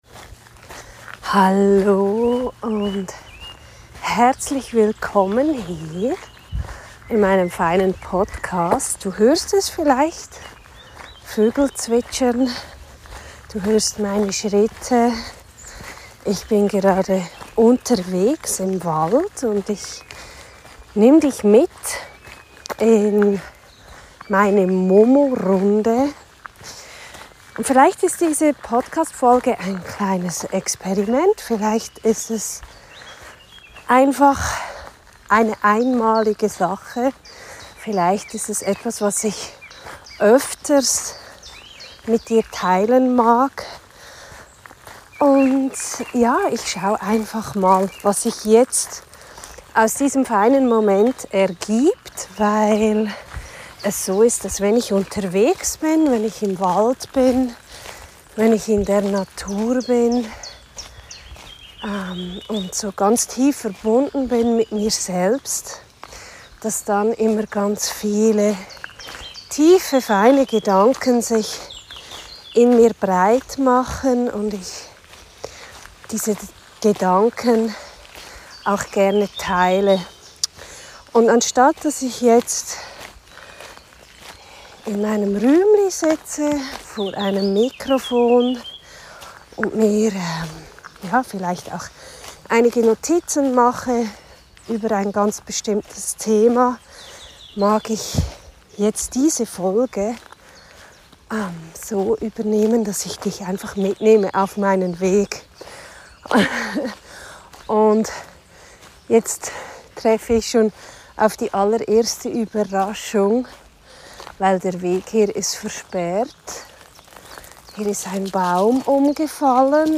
In dieser Episode nehme ich dich mit auf einen Spaziergang durch den Wald, wo ich meine tiefen Gedanken und Erkenntnisse mit dir teile.